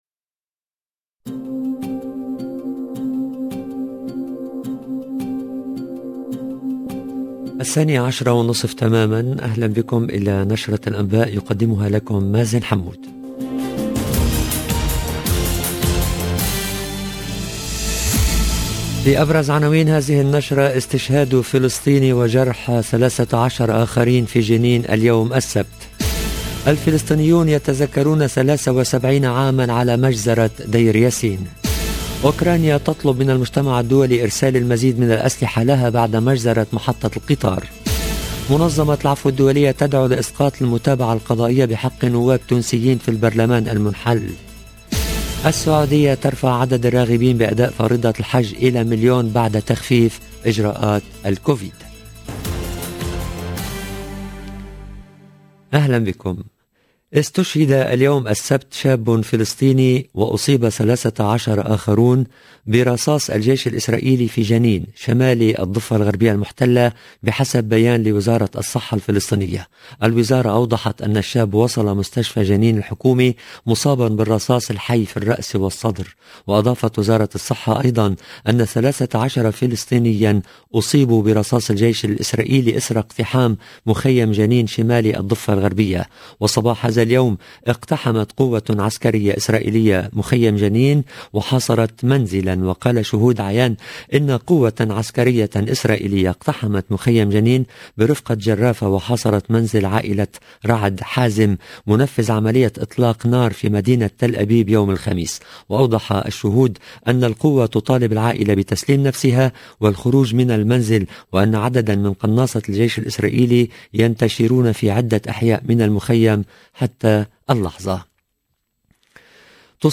LE JOURNAL DE MIDI 30 EN LANGUE ARABE DU 9/04/22